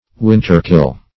Winterkill \Win"ter*kill`\, v. t. [imp. & p. p. Winterkilled;